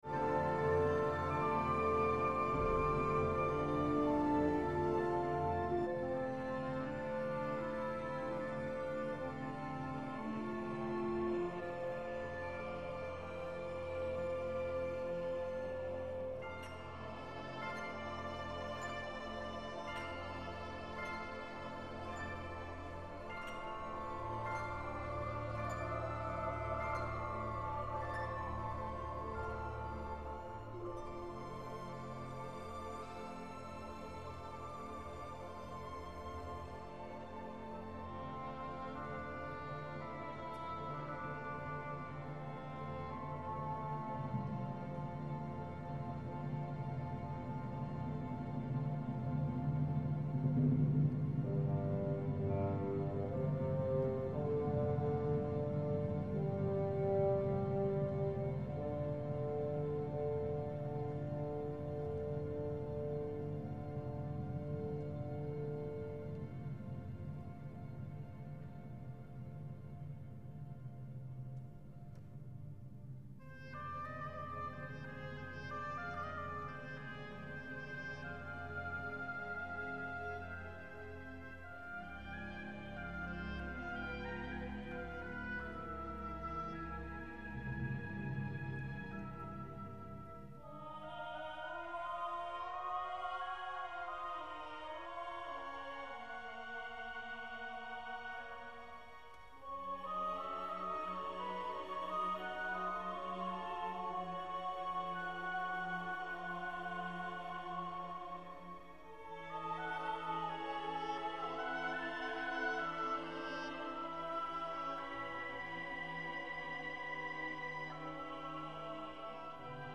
His last mountain-themed work was The Song of the High Hills (1911) for mixed chorus and large orchestra.
The chorus in this work sings no words; pure vocalization is employed as a means of poetic evocation.